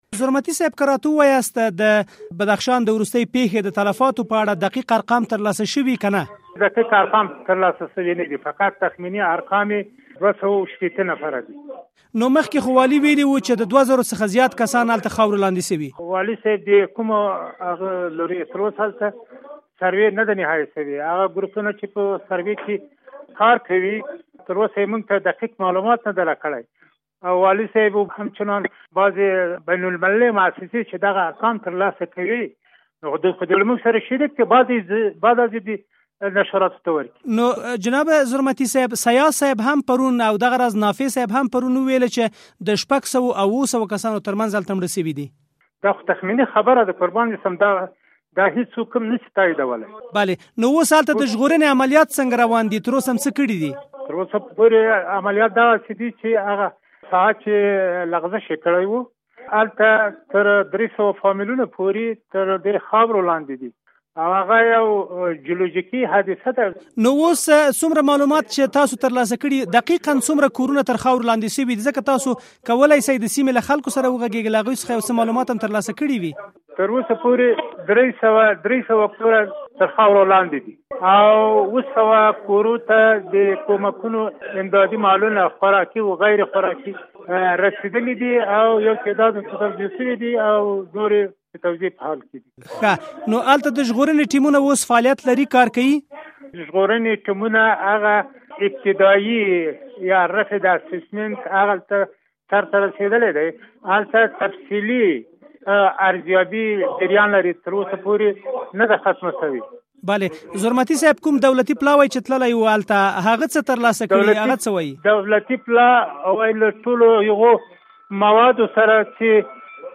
له انجنیر سردار محمد زرمتي سره مرکه